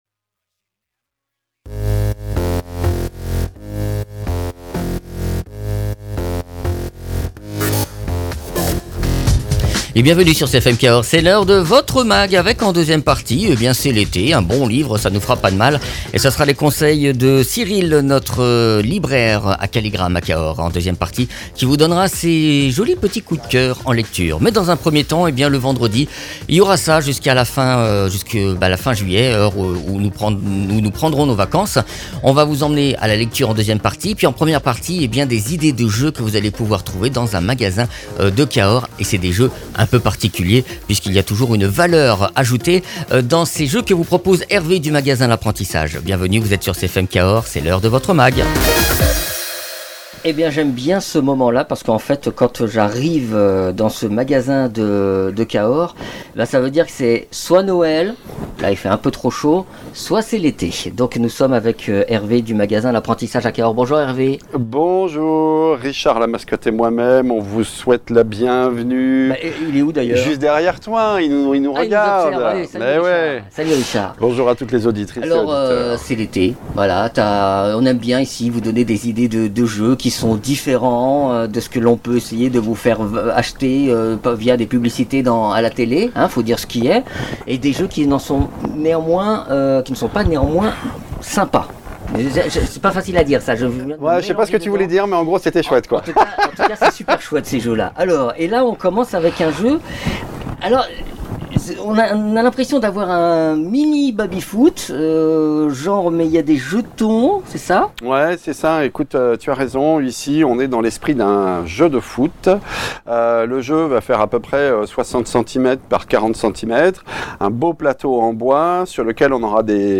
maitre des jeux.
libraire